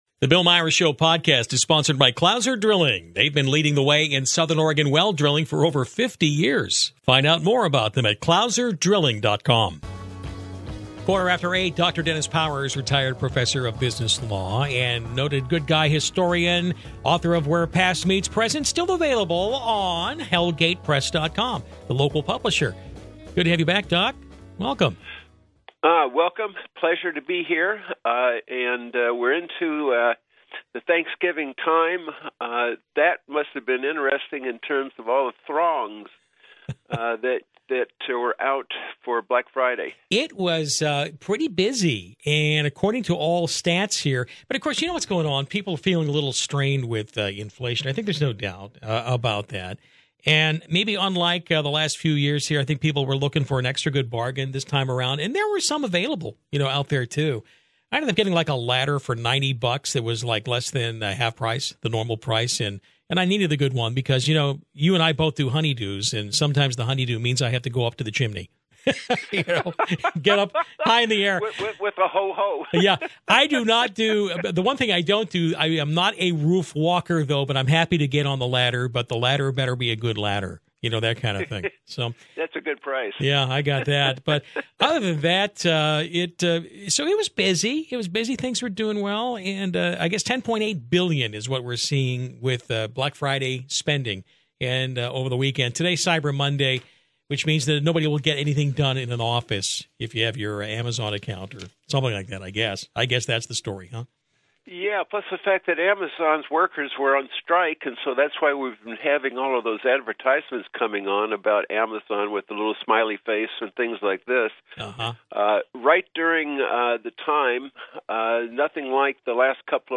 Morning News